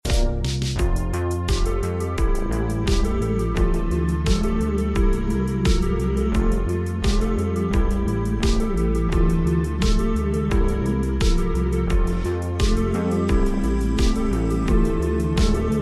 (Slowed)